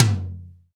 Index of /90_sSampleCDs/Roland L-CDX-01/KIT_Drum Kits 6/KIT_Parched Kit
TOM ATTAK 05.wav